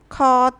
10-s2-khaa-careful.wav